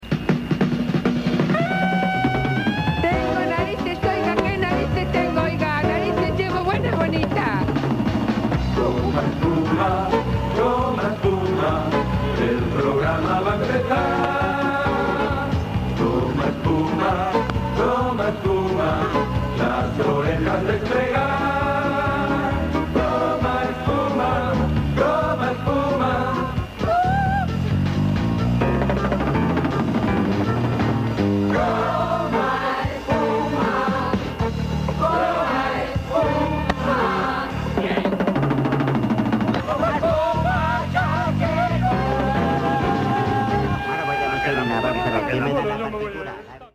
Cançó d'entrada del programa